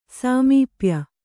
♪ sāmīpya